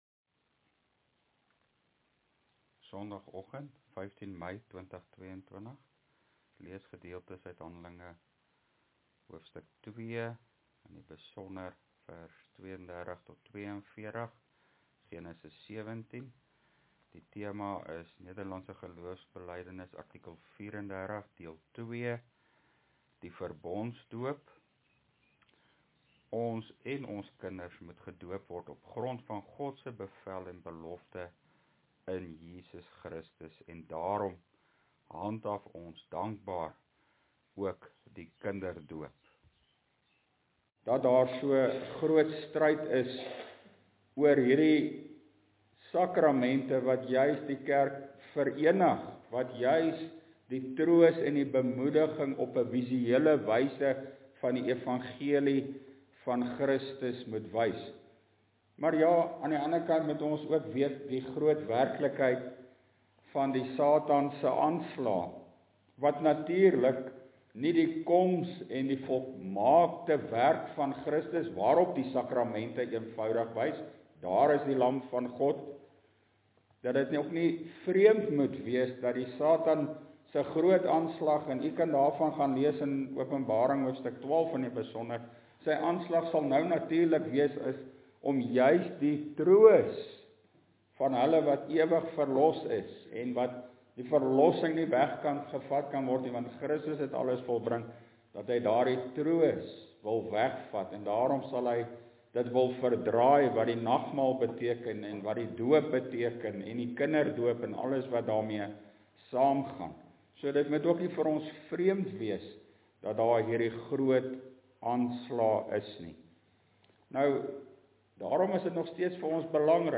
(Preekopname: GK Carletonville, 2022-05-12 , nota: let wel, die inhoud van die preek en teksnotas hier onder stem nie altyd ooreen nie, die notas is nie volledig nie, die audio preek is die volledige preek).